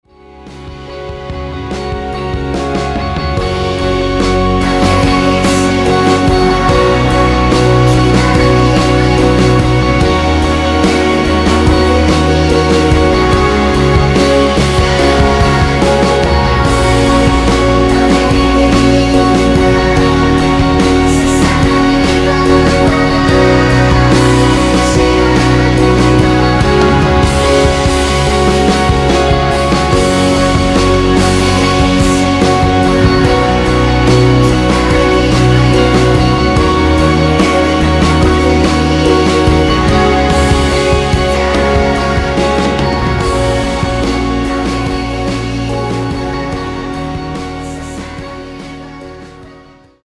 후반부 4분 14초 부터 5분 4초까지 코러스가 나오며 다른 부분은 원곡에 코러스가 없습니다.
원키에서(+4)올린 코러스 포함된 MR입니다.(미리듣기 확인)
F#
앞부분30초, 뒷부분30초씩 편집해서 올려 드리고 있습니다.